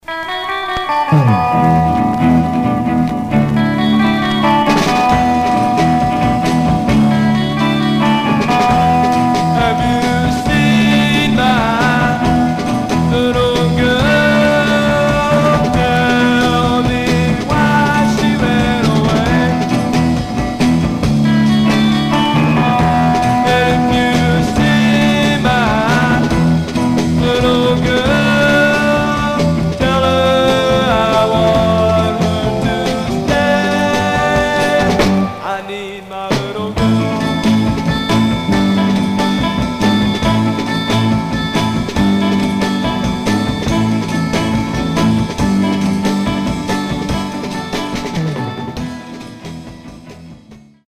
Some surface noise/wear Stereo/mono Mono
Garage, 60's Punk